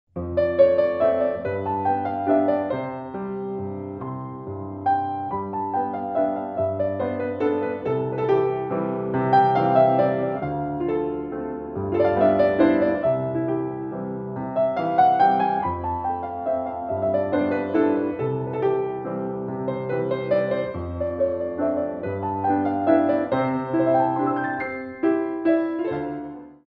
Ballet Music for All Level Classes
Solo Piano
Moderate Waltzes